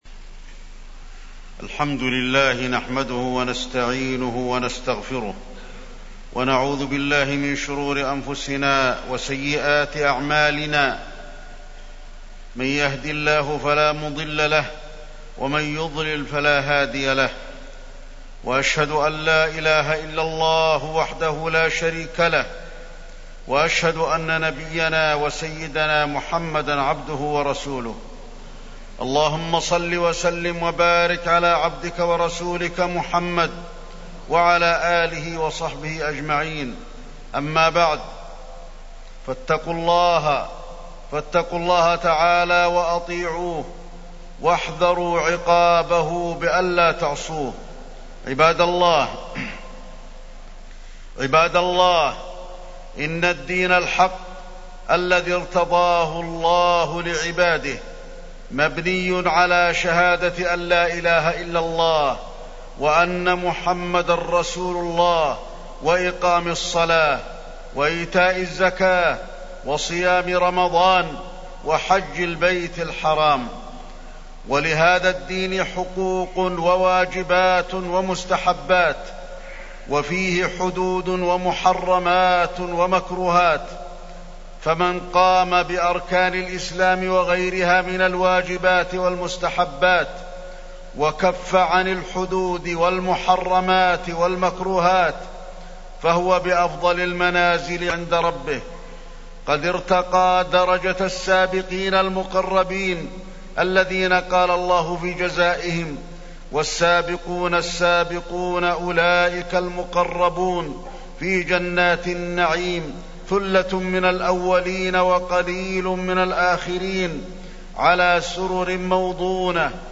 تاريخ النشر ٧ جمادى الآخرة ١٤٢٨ هـ المكان: المسجد النبوي الشيخ: فضيلة الشيخ د. علي بن عبدالرحمن الحذيفي فضيلة الشيخ د. علي بن عبدالرحمن الحذيفي الأخوة بين المؤمنين